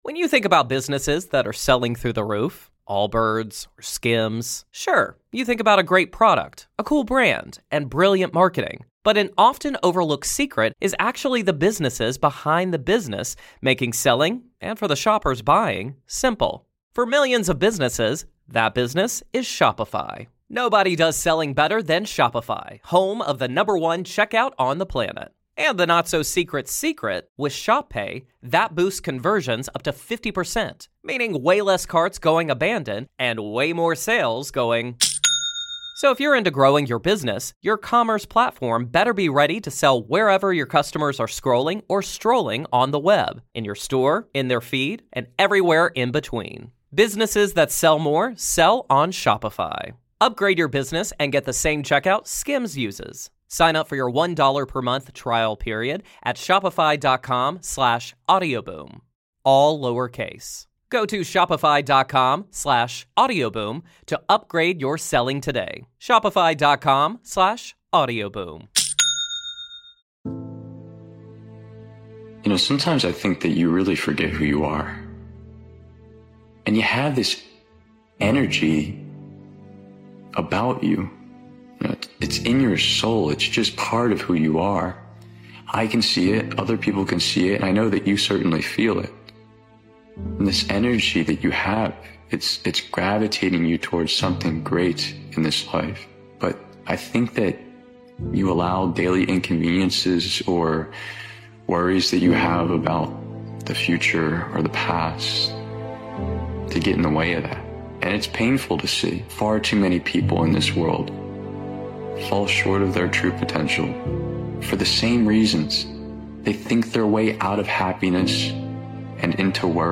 This is the most powerful motivational speech compilation that will inspire you to break free from mediocrity and strive for greatness.